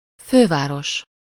Ääntäminen
IPA: [ka.pi.tal]